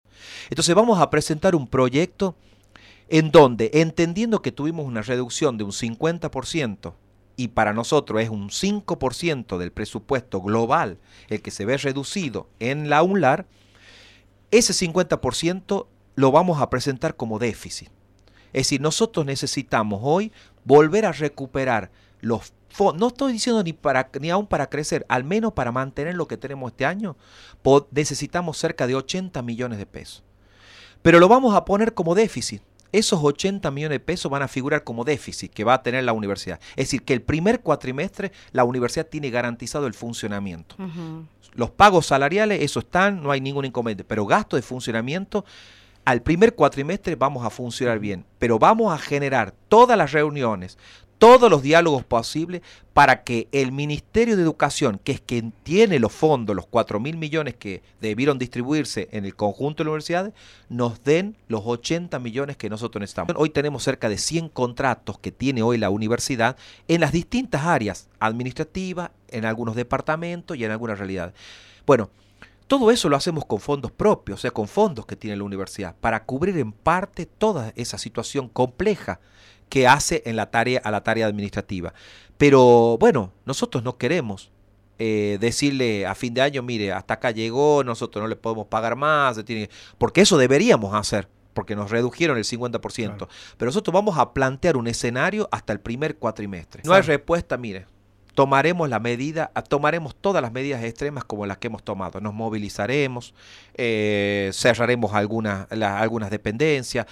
En diálogo con Radio UNLaR, el Rector explicó que presentarán un proyecto en el que, entendiendo la reducción del 50 por ciento en gastos de funcionamiento, se lo presentará como déficit.
La entrevista a Calderón